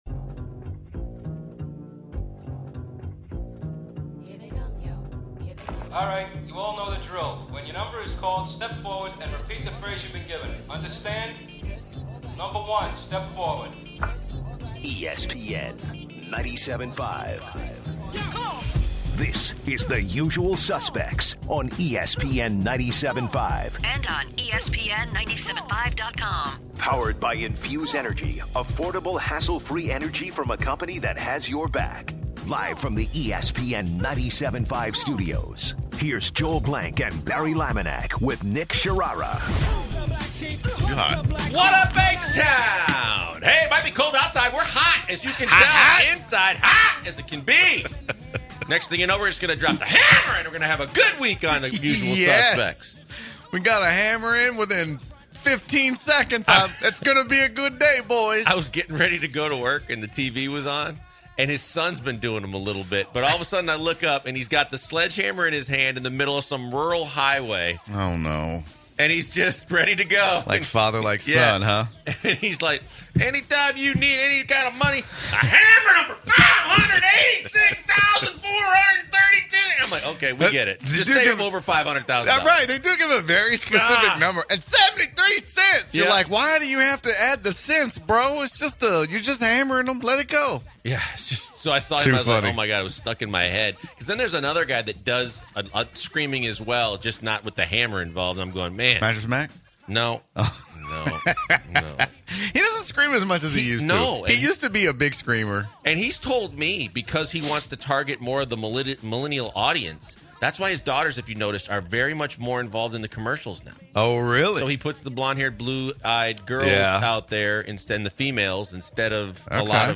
The guys get the first hour of the show rolling with some stories from the hosts about their weekend, including some of their patented lawn talk!